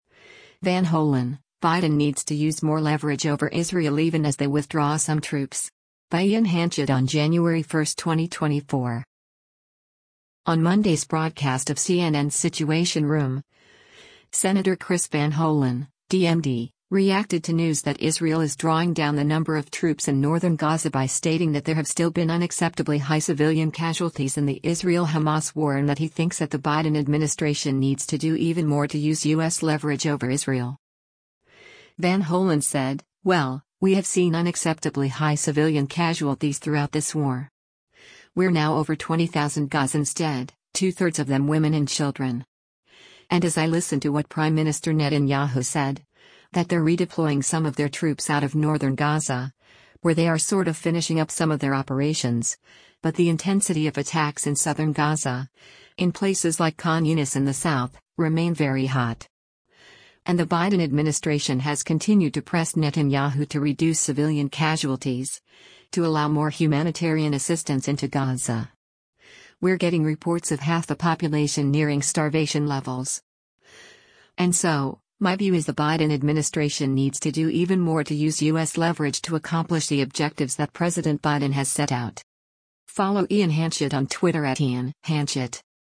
On Monday’s broadcast of CNN’s “Situation Room,” Sen. Chris Van Hollen (D-MD) reacted to news that Israel is drawing down the number of troops in northern Gaza by stating that there have still been “unacceptably high civilian casualties” in the Israel-Hamas war and that he thinks that “the Biden administration needs to do even more to use U.S. leverage” over Israel.